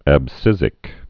(ăb-sĭzĭk)